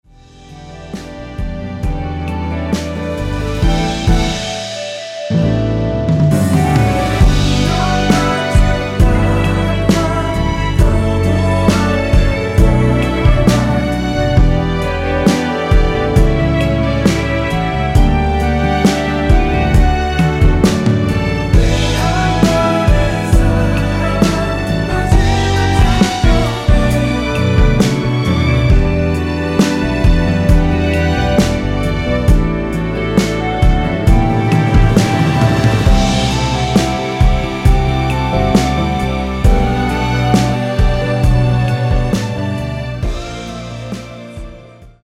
원키에서(-1)내린 멜로디와 코러스 포함된 MR입니다.(미리듣기 확인)
앞부분30초, 뒷부분30초씩 편집해서 올려 드리고 있습니다.
중간에 음이 끈어지고 다시 나오는 이유는